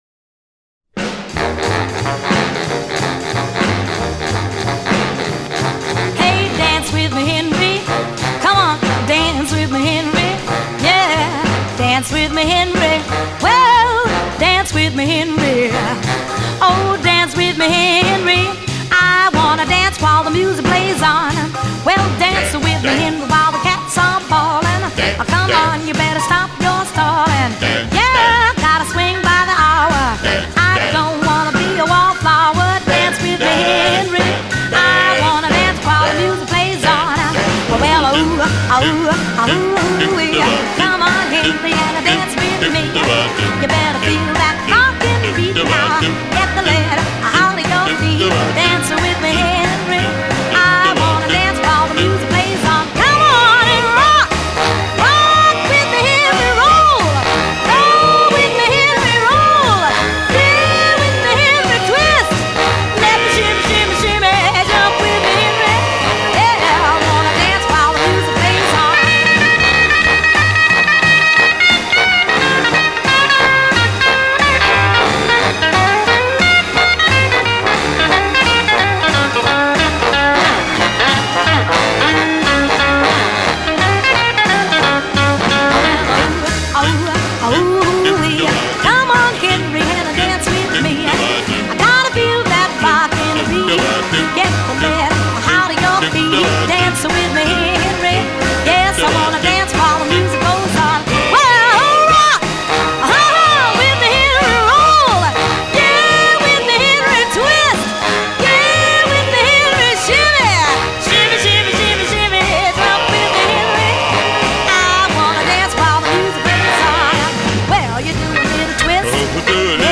Genre: Pop
Style: Vocal